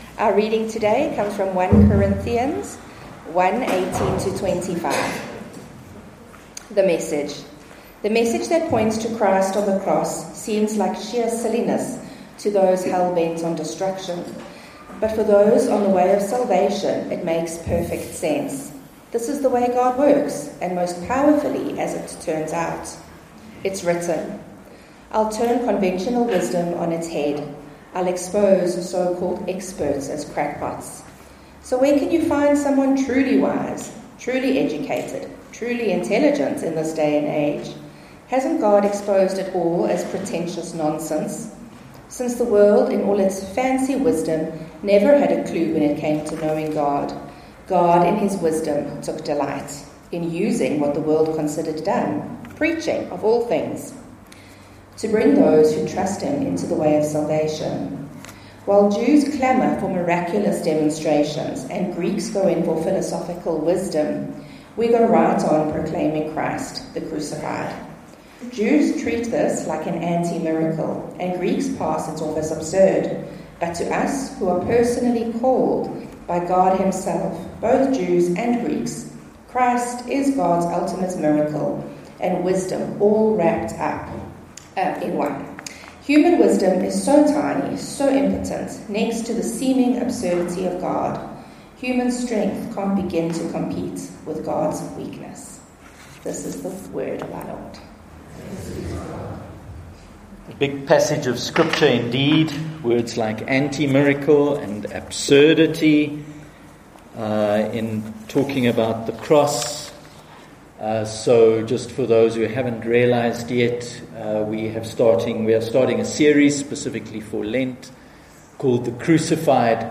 9:30am Connect Service from Trinity Methodist Church, Linden, Johannesburg
Sermons